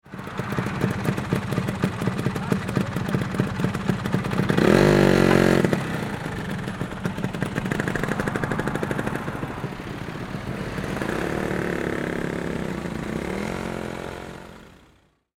Vintage-vespa-scooter-joining-city-traffic-sound-effect.mp3